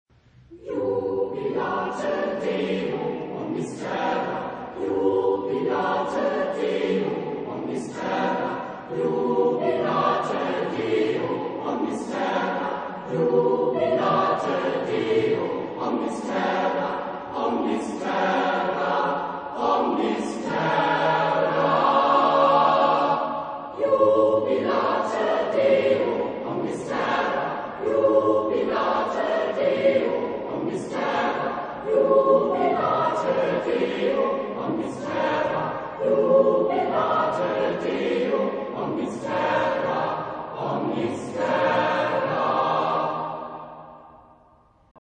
Genre-Style-Forme : Sacré ; Motet ; Psaume
Type de choeur : SSAAATTBBB  (10 voix mixtes )
Tonalité : aléatoire ; libre